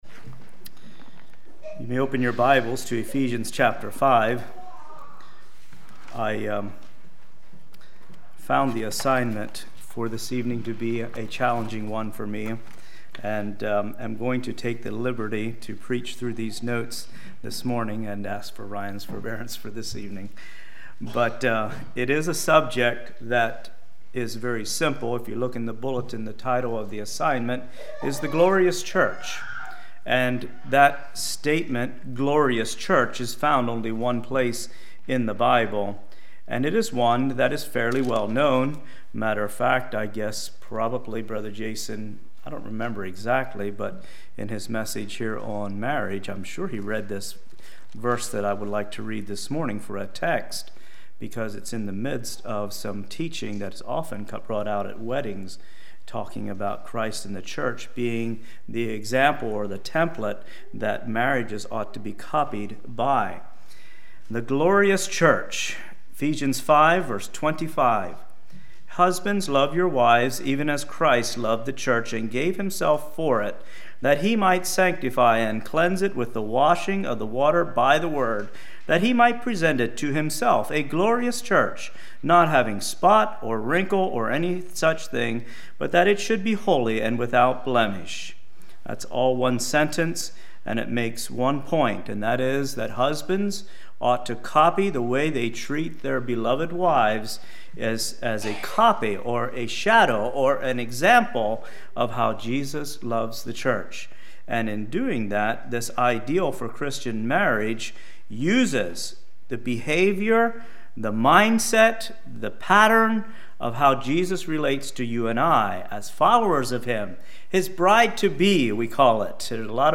2019 Sermon ID